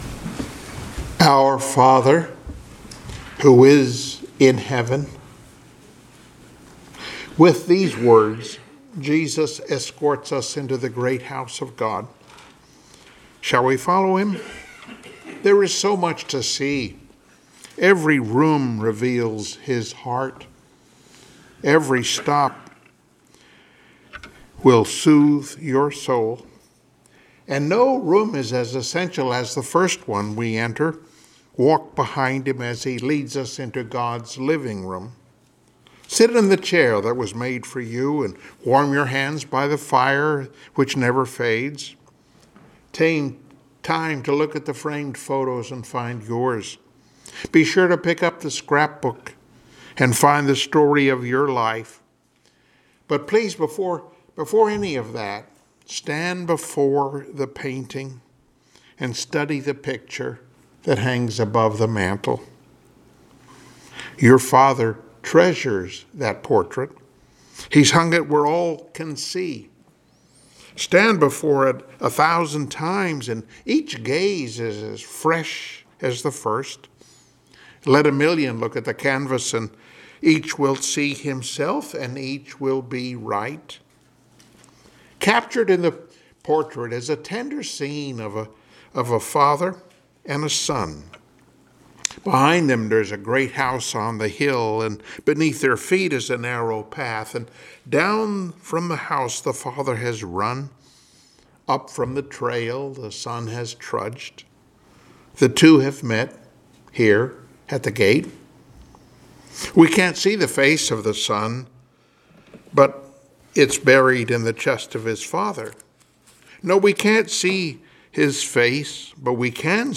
Luke 15:21-24 Service Type: Sunday Morning Worship Topics